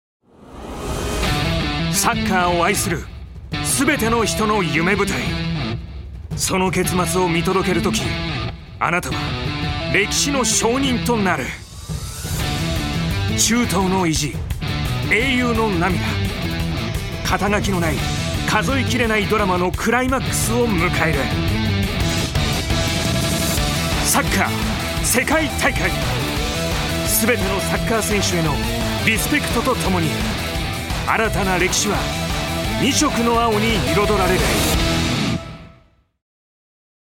所属：男性タレント
ナレーション８